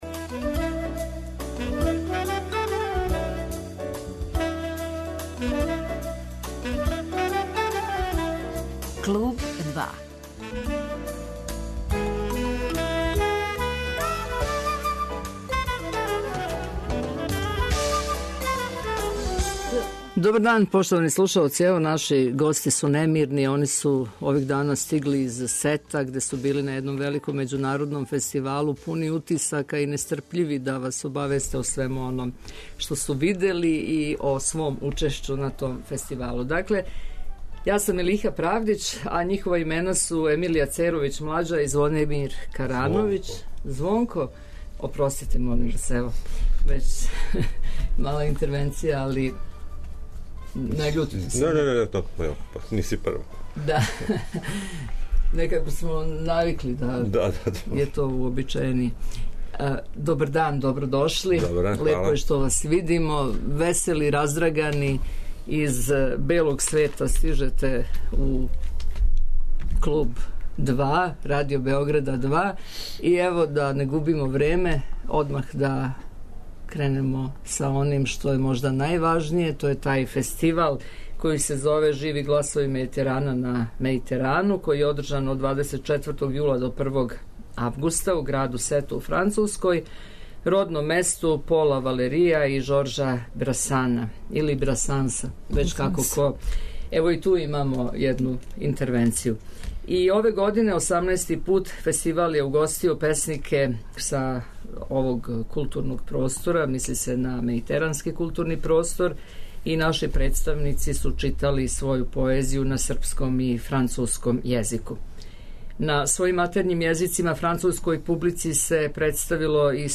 И ове године, 18. пут фестивал је угостио песнике са овог културног простора. Наши представници су читали своју поезију на српском и француском језику.